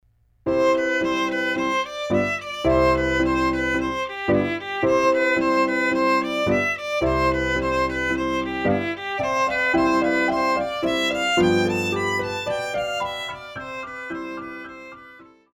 Classical
Piano
Etudes,Methods
Solo with accompaniment